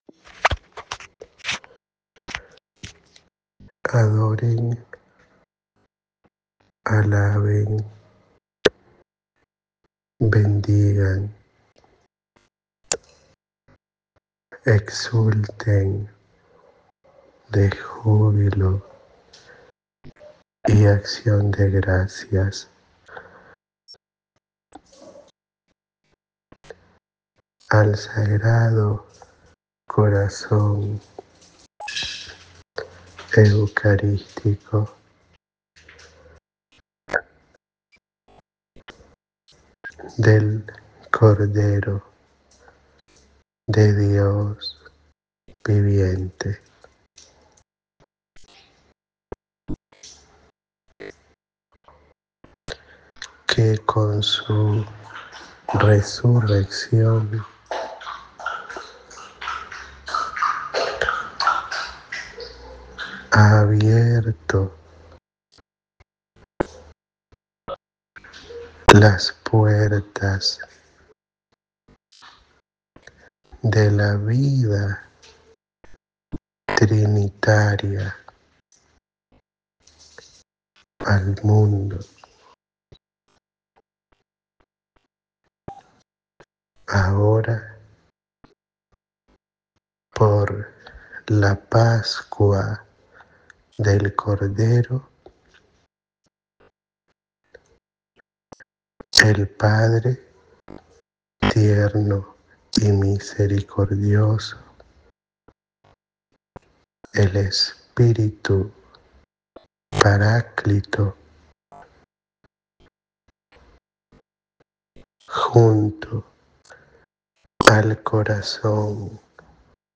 Audio da Mensagem